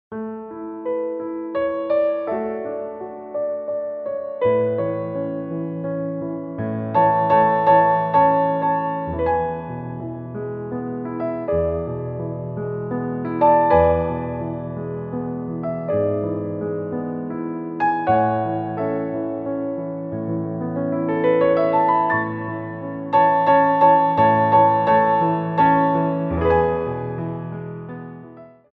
Adage
3/4 (8x8)